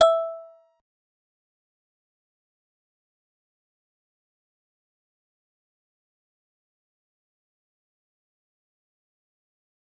G_Musicbox-E5-pp.wav